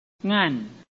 臺灣客語拼音學習網-客語聽讀拼-海陸腔-鼻尾韻
拼音查詢：【海陸腔】ngan ~請點選不同聲調拼音聽聽看!(例字漢字部分屬參考性質)